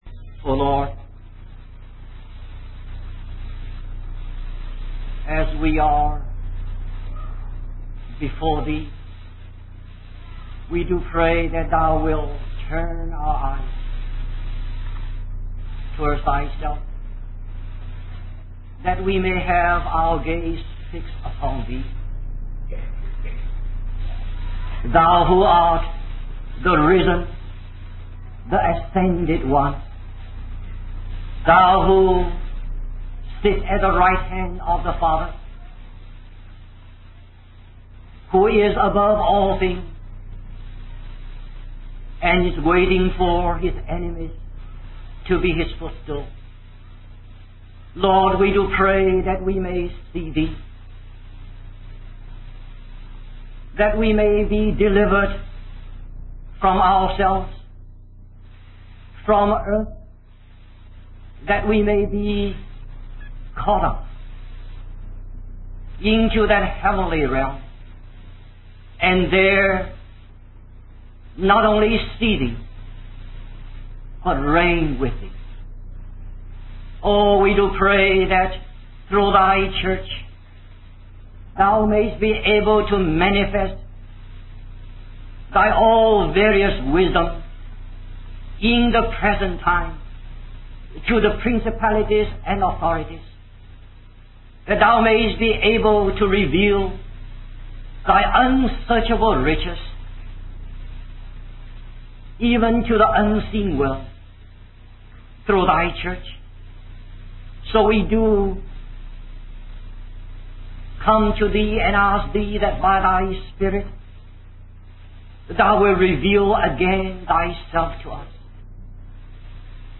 In this sermon, the speaker uses the analogy of a centipede with a hundred feet to illustrate the danger of overthinking and self-focus. He emphasizes the importance of fixing our eyes on Christ and being joined to Him as members of His body.